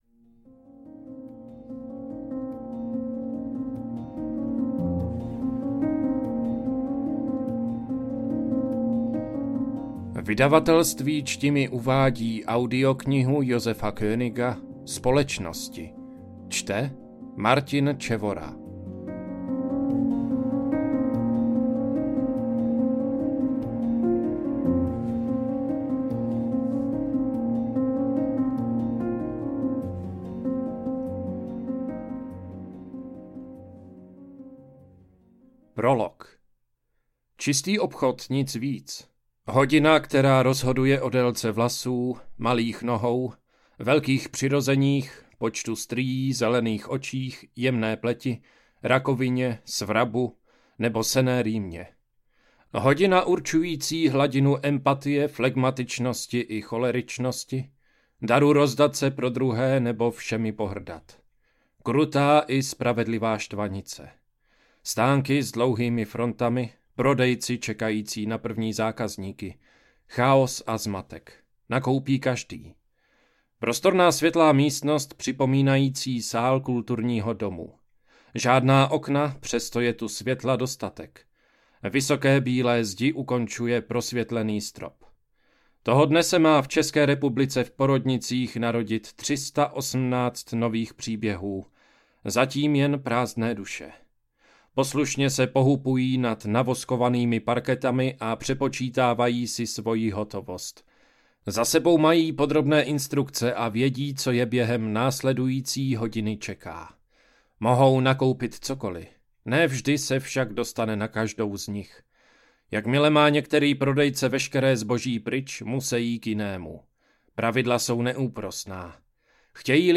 Kategorie: Povídkové